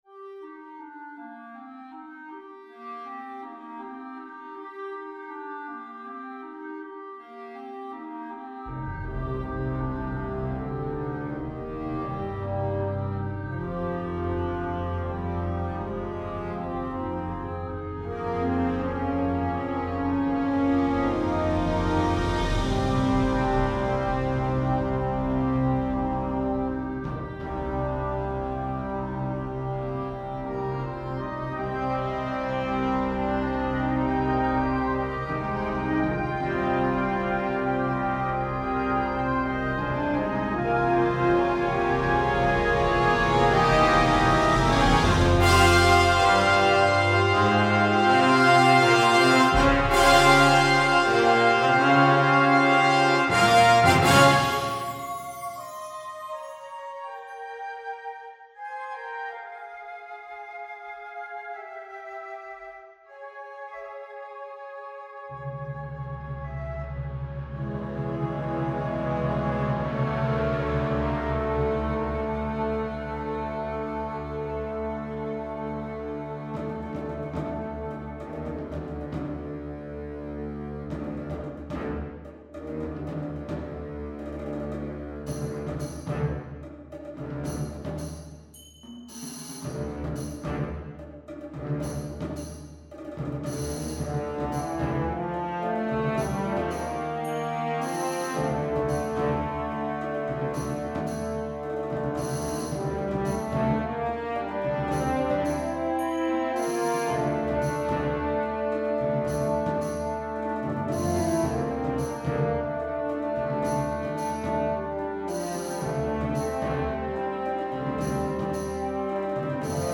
Partitions pour orchestre d'harmonie.
• View File Orchestre d'Harmonie